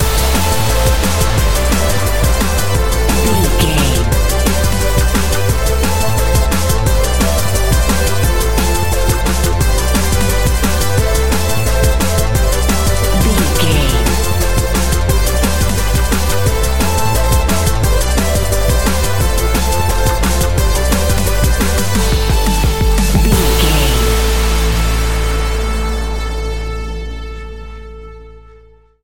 Epic / Action
Fast paced
Aeolian/Minor
Fast
aggressive
dark
driving
energetic
futuristic
synthesiser
drum machine
electronic
sub bass
synth leads
synth bass